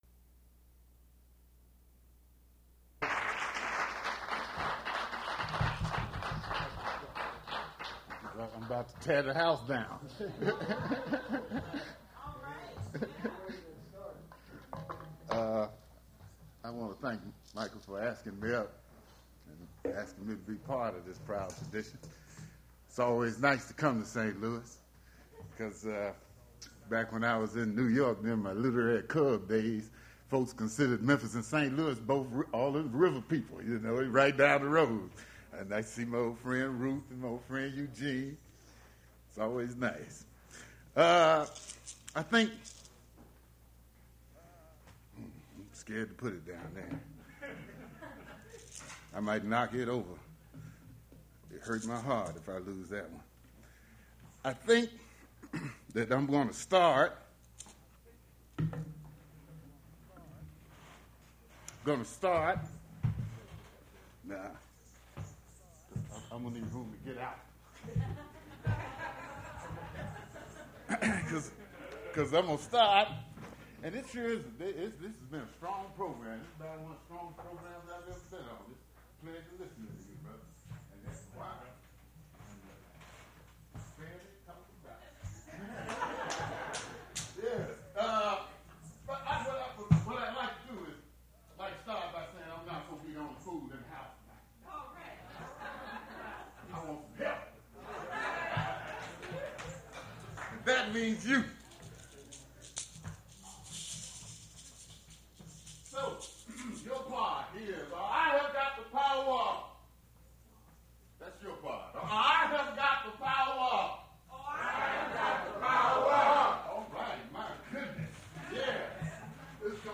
Prose reading featuring Arthur Flowers
Attributes Attribute Name Values Description Arthur Flowers prose reading at Duff's Restaurant.
mp3 edited access file was created from unedited access file which was sourced from preservation WAV file that was generated from original audio cassette.